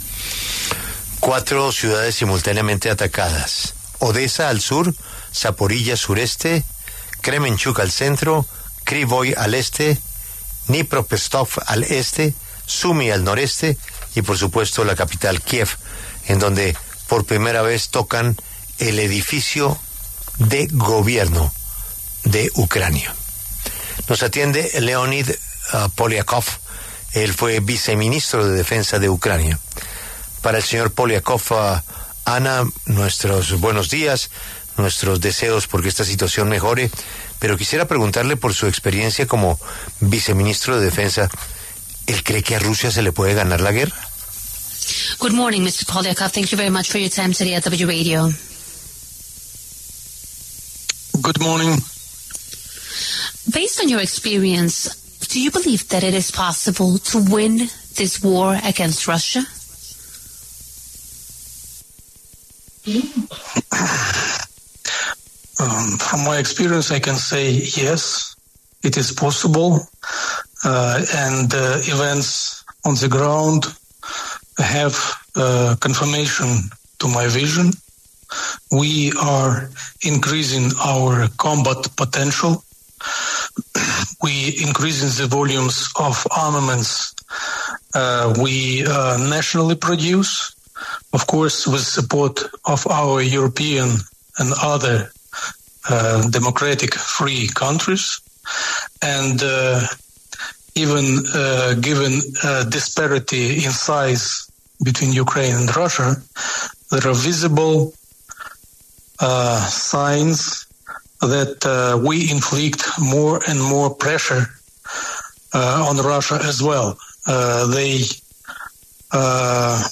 Leonid Polyakov, exviceministro de Defensa de Ucrania, habló en La W sobre la reciente escalada de la ofensiva rusa con un nuevo récord de drones lanzados contra varias regiones y ciudades el país, incluida Kiev.